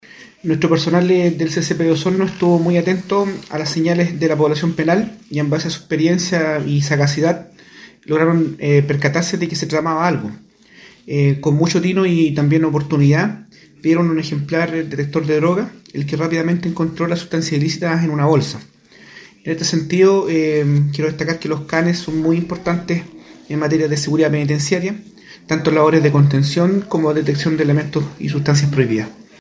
Por su parte, el Seremi de Justicia y Derechos Humanos, Cristóbal Fuenzalida Palma, expresó que “el equipo de Canes Adiestrados de Gendarmería Los Lagos, día a día se encuentran listos y preparados para efectuar ingresos y realizar la detección de sustancias prohibidas al interior de nuestros recintos penitenciarios. Por eso, felicito el arduo trabajo y la dedicación de los funcionarios”.